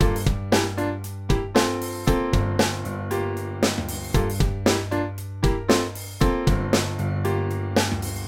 vintage-drive-default.mp3